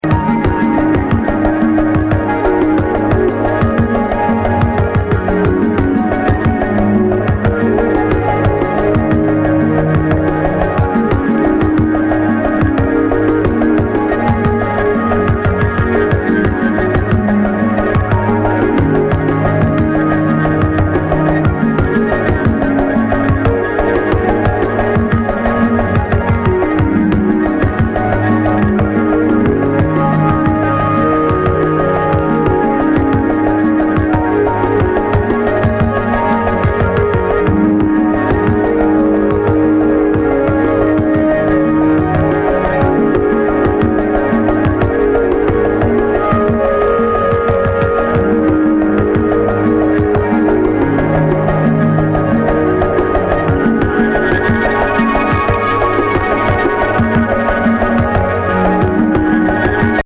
suspenseful and gloomy